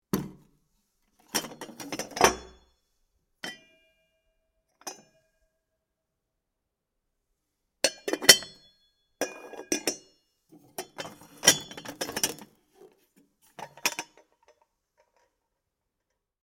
Lunch box